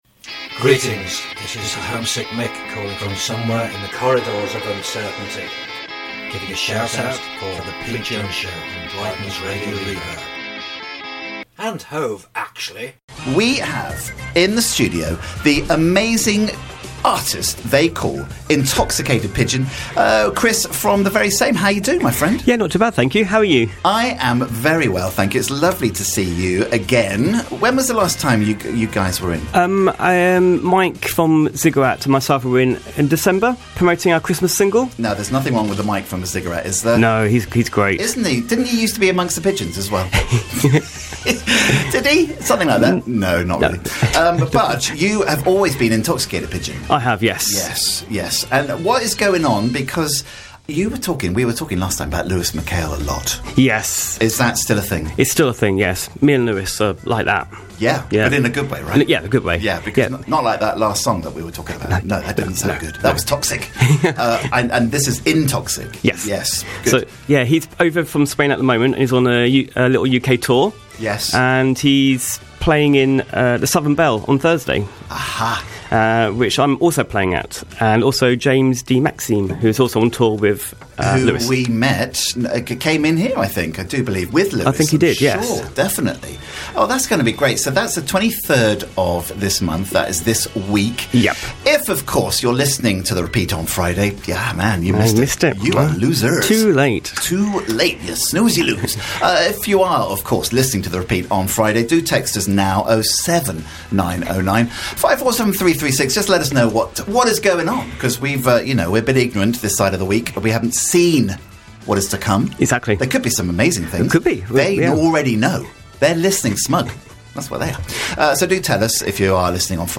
2 live tracks and a studio recording plus chat